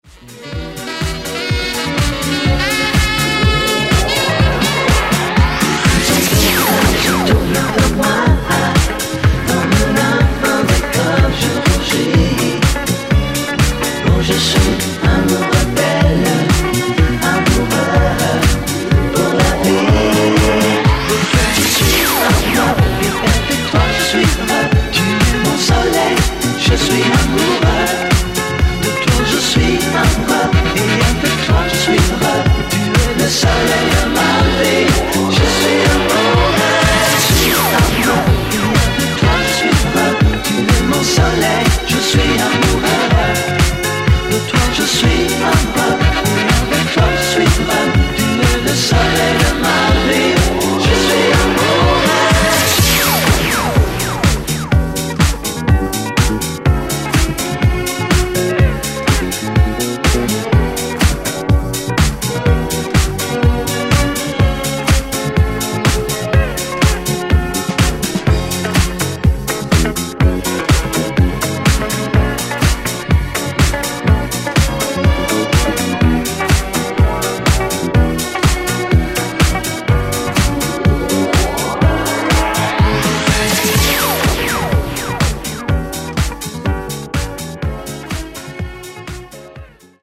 Genre: HIPHOP
Clean BPM: 88 Time